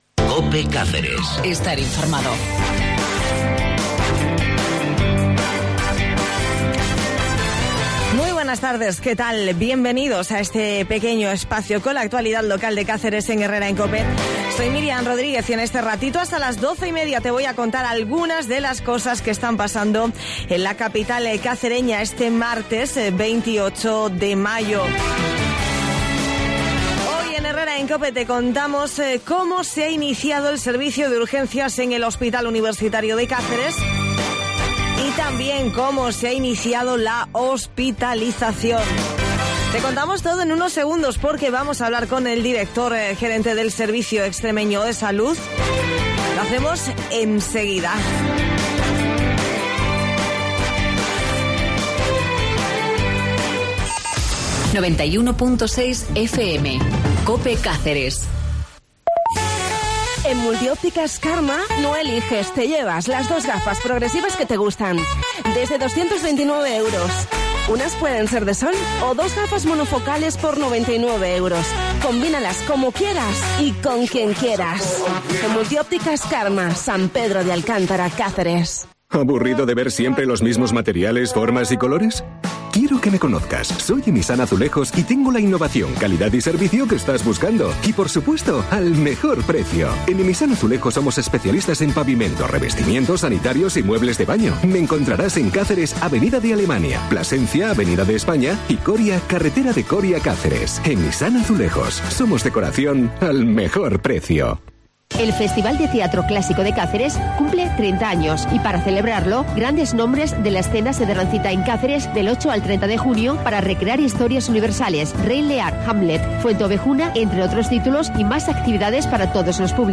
En Herrera en Cope hablamos con el director gerente del SES sobre las urgencias y la hospitalización en el HUC
Esta mañana han comenzado a funcionar las urgencias en el Hospital Universitario de Cáceres y el traslado de pacientes desde el Hospital Virgen de la Montaña y del San Pedro de Alcántara hasta el nuevo hospital donde también hoy comienza a funcionar la hospitalización. Te contamos todos los detalles en Herrera en Cope con el director gerente del Servicio Extremeño de Salud, Ceciliano Franco.